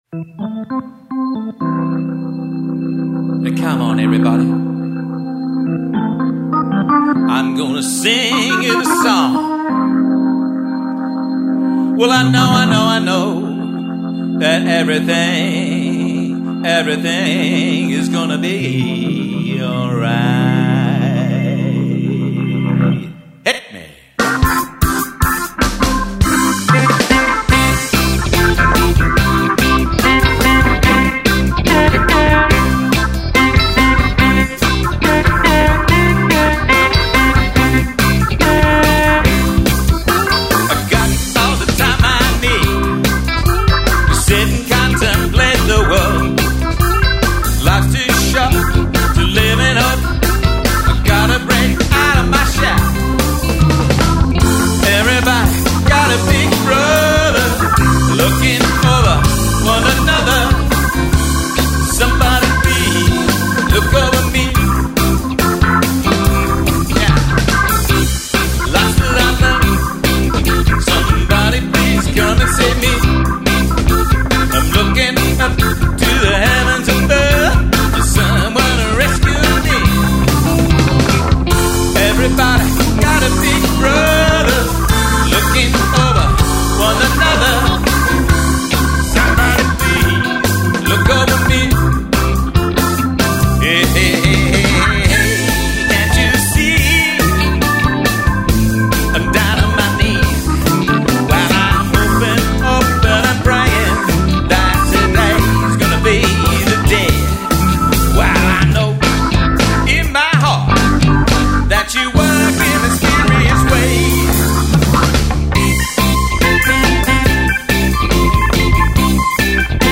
Cargill Custom Telecaster
You can hear this guitar in a recent (2009) live studio recording with my '66 Ultratone
here with its original pickups.